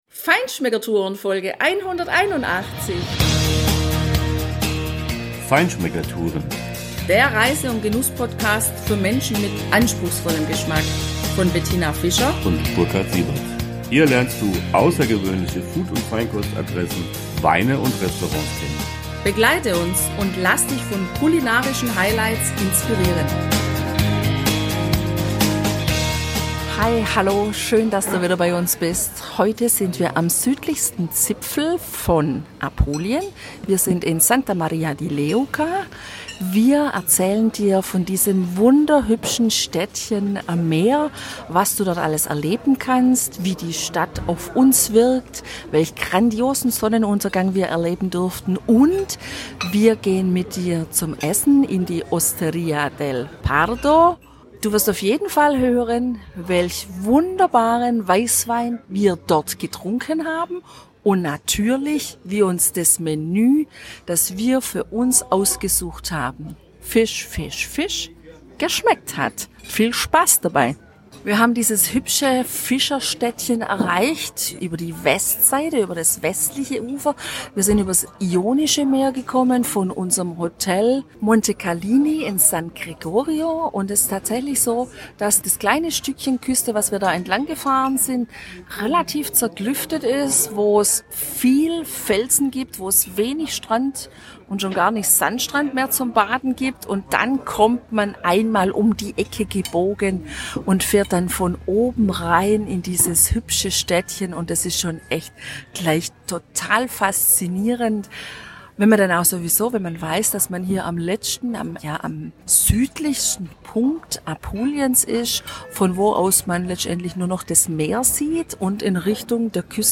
Die hatten wir in der Hosteria del Pardo, und wir beschreiben dir live den wunderbaren Verdeca und ein Fisch-Menü, welches wir dort genossen haben.